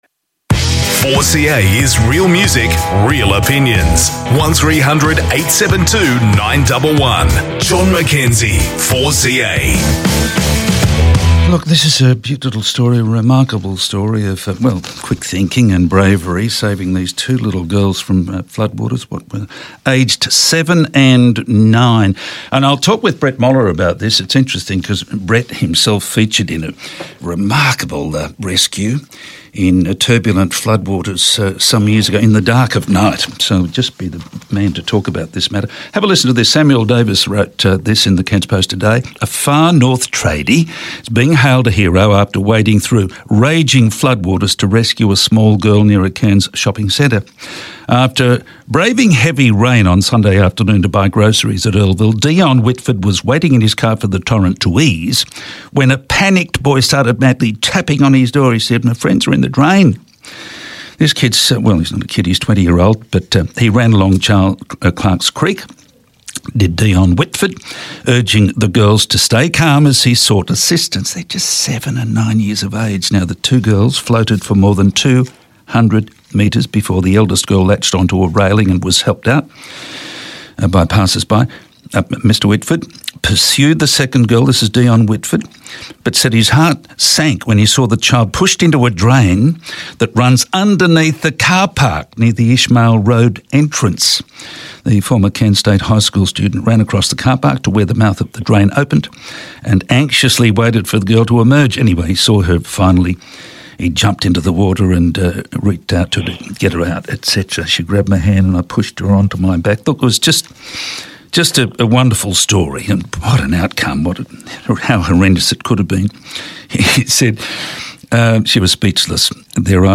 chats with Councillor Brett Moller, CRC representative for division 1, about the courageous rescue of a child from floodwaters by a young local tradesman.